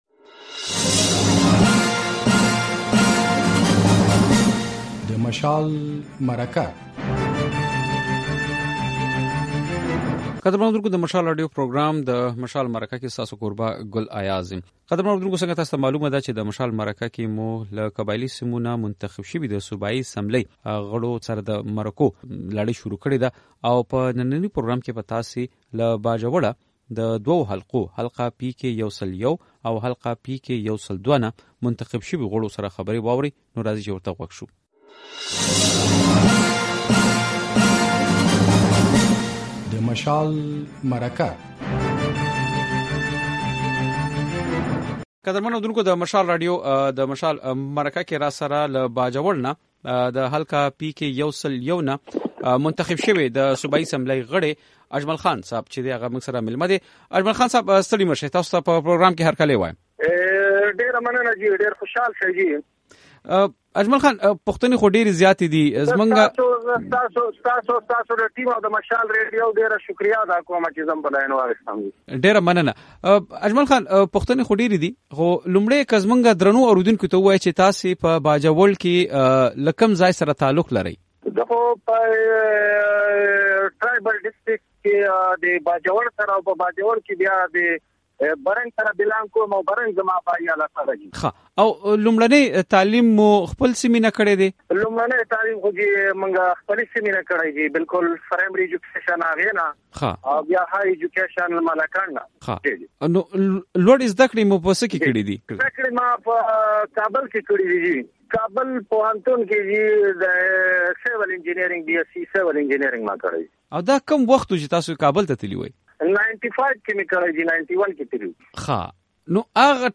صوبايي اسمبلۍ کې د باجوړ له استازو سره د مشال مرکه